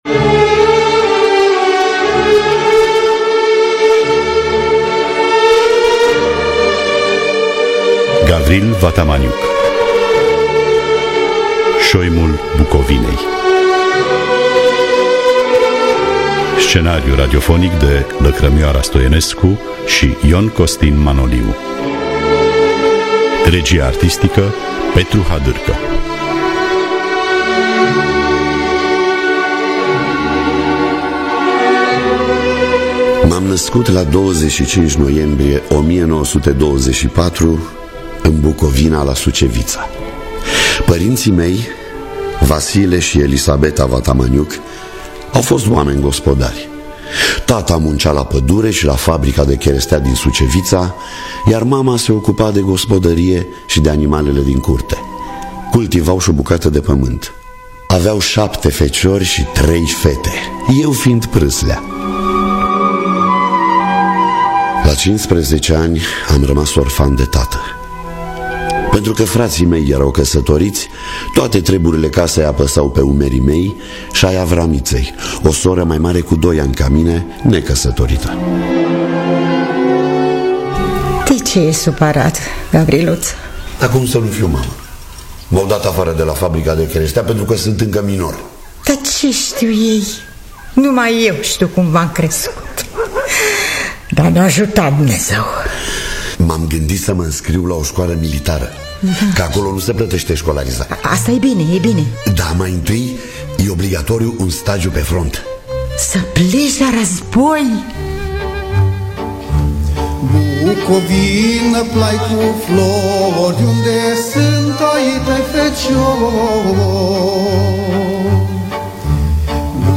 Scenariu radiofonic